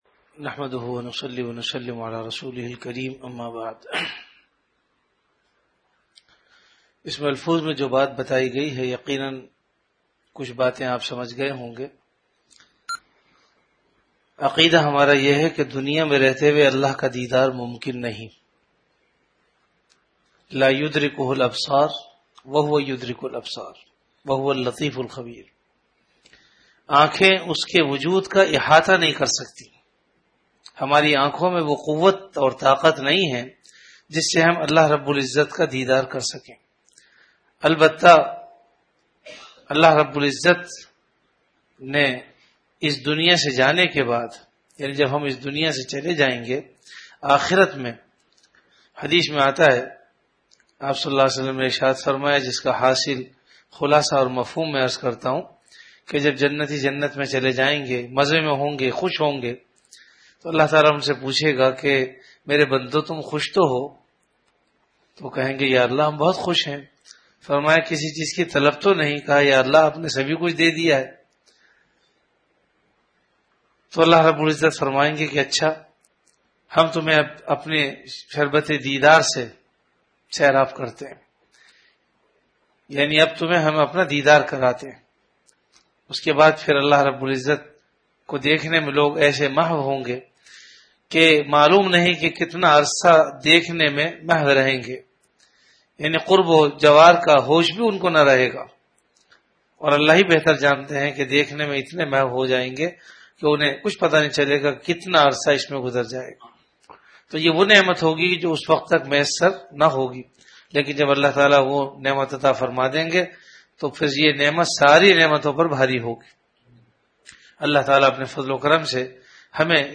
Majlis-e-Zikr · Jamia Masjid Bait-ul-Mukkaram, Karachi